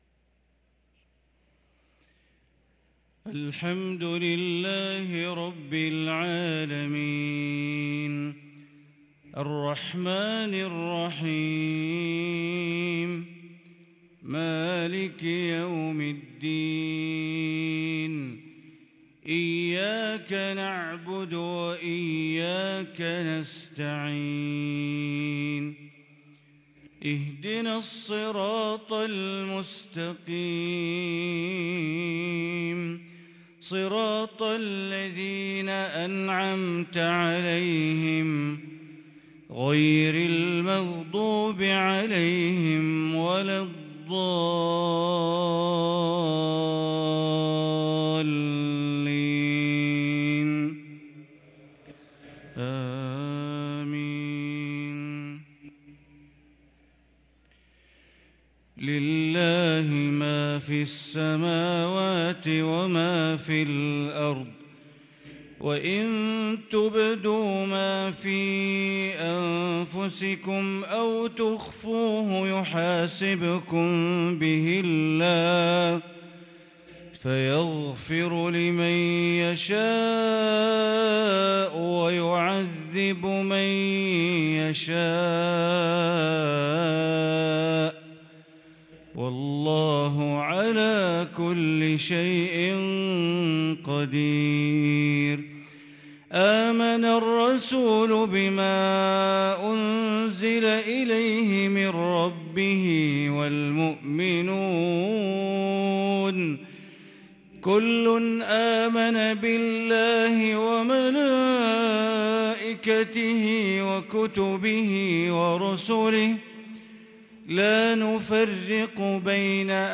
صلاة المغرب للقارئ بندر بليلة 24 ربيع الآخر 1444 هـ